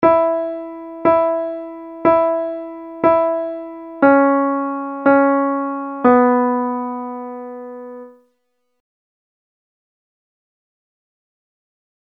Key written in: E Major
Comments: Nice gentle reassuring tag.
Each recording below is single part only.